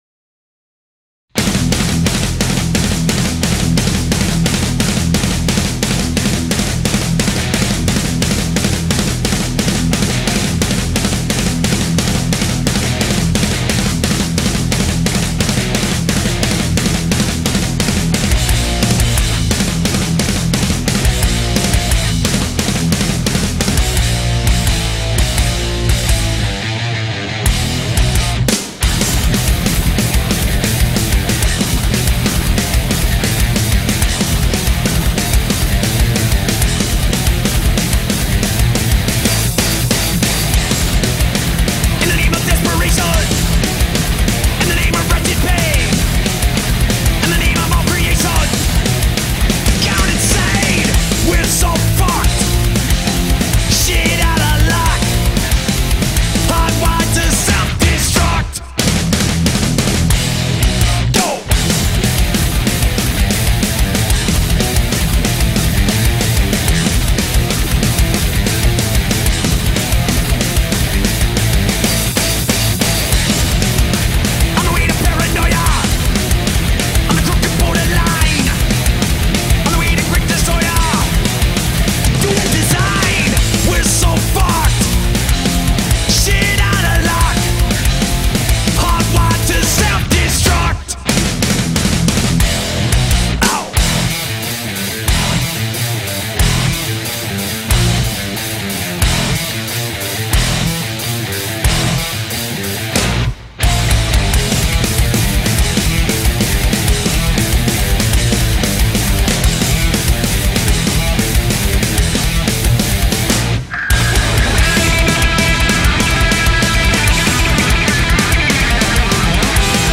Heavy Metal, Thrash Metal